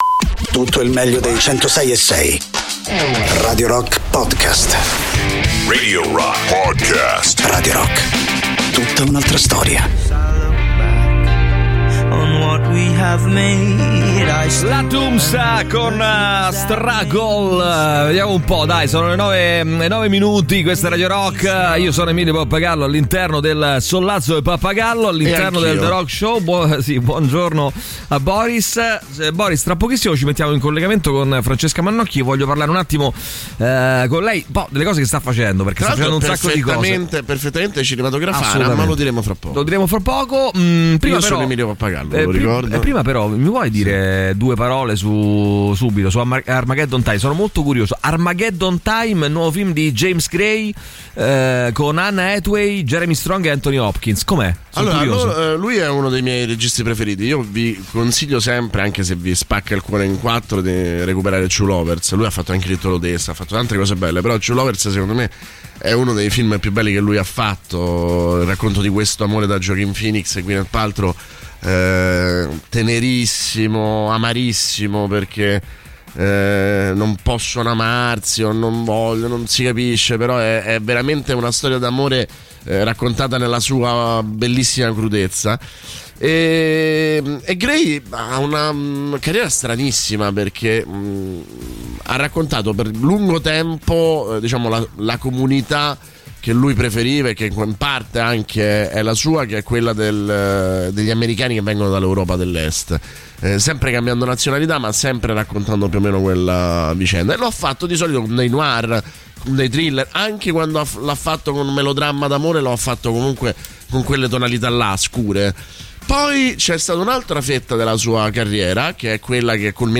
Ospite telefonico della puntata: Francesca Mannocchi.